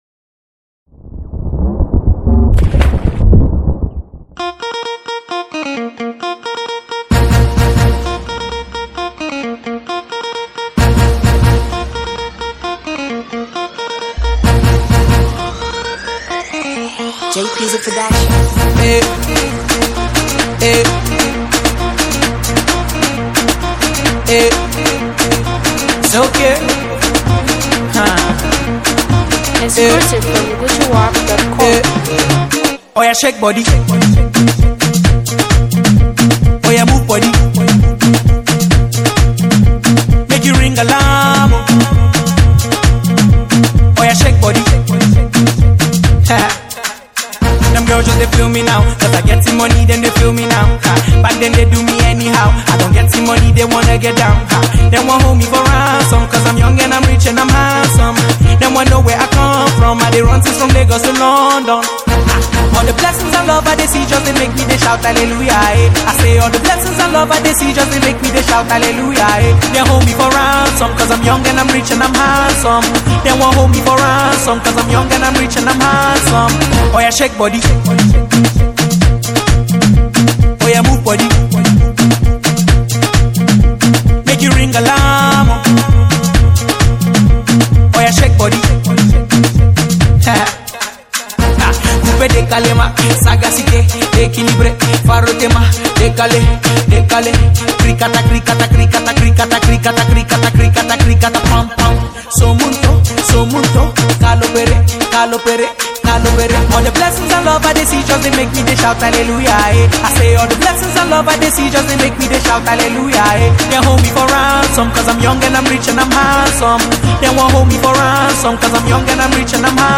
” a high-energy track that showcases his signature vocals.